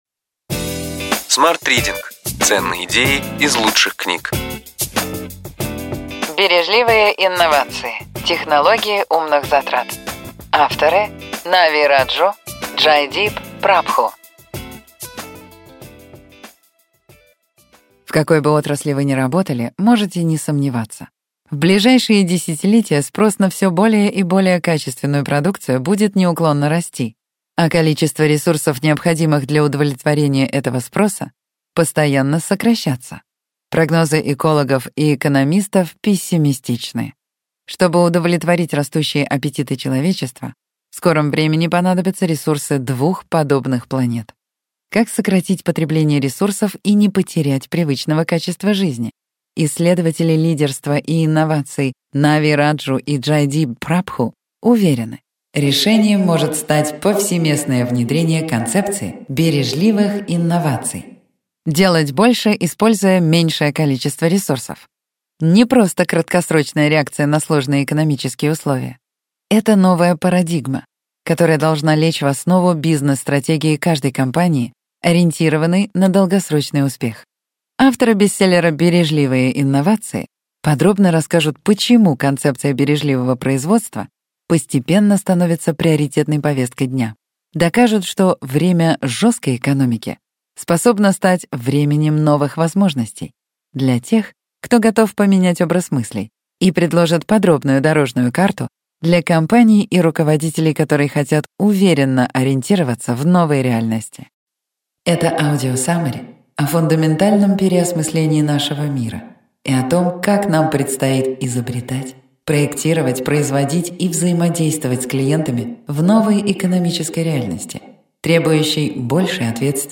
Аудиокнига Ключевые идеи книги: Бережливые инновации. Технологии умных затрат.